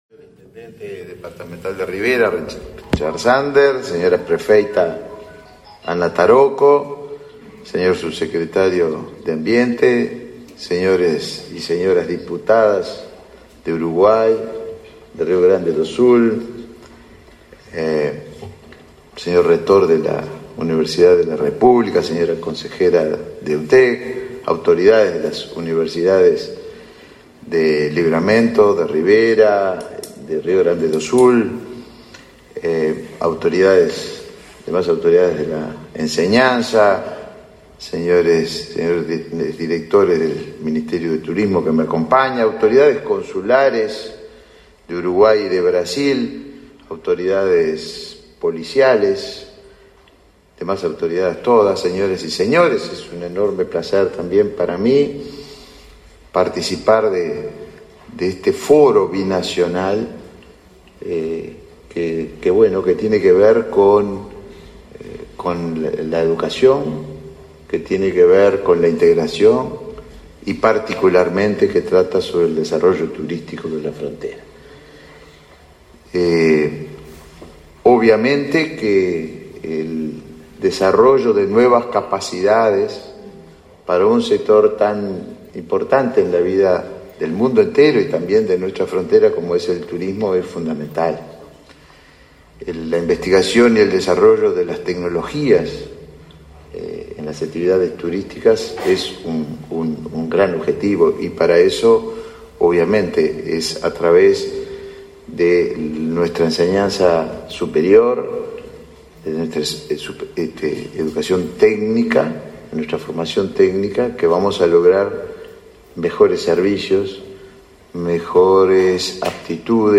Palabras del ministro de Turismo, Tabaré Viera
El ministro de Turismo, Tabaré Viera, participó en el Foro de Integración Brasil-Uruguay, realizado en Rivera.
viera prensa.mp3